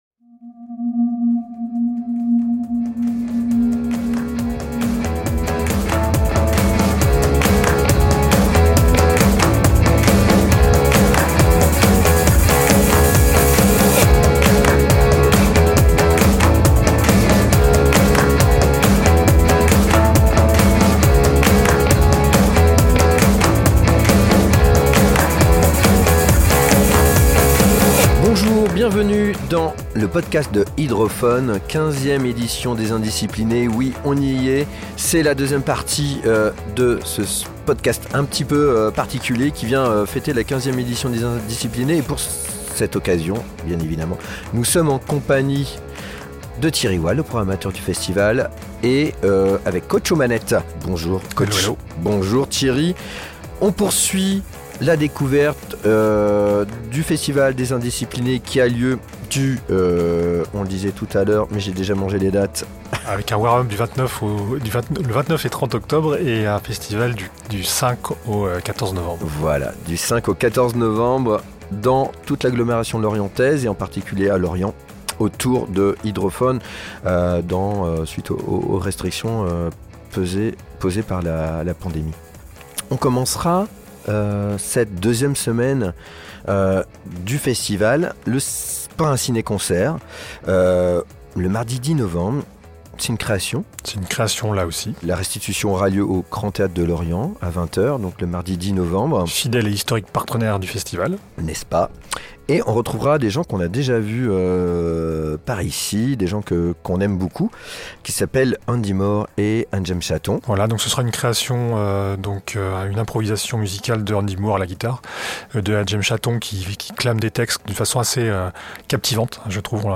Présentation de la programmation de la 15ème édition, enregistrée le 16 octobre dans un studio à Hydrophone